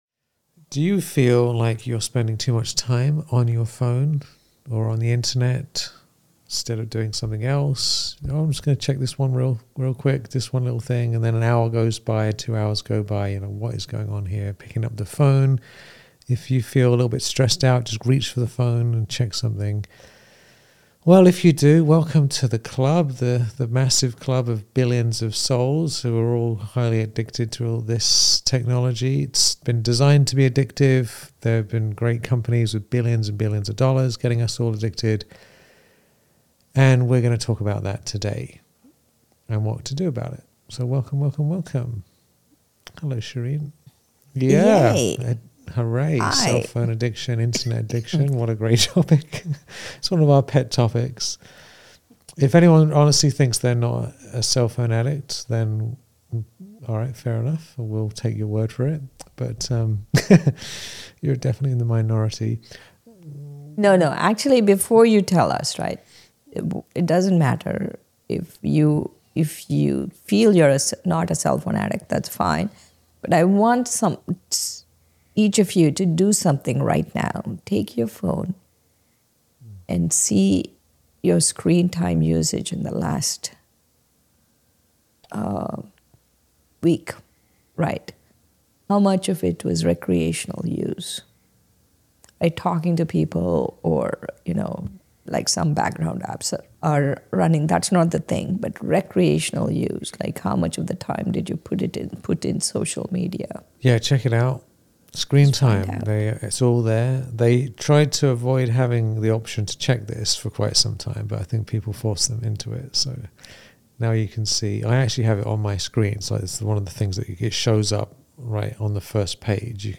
In this conversation, we look at why the mind becomes dependent on constant input and how awareness can help you regain control.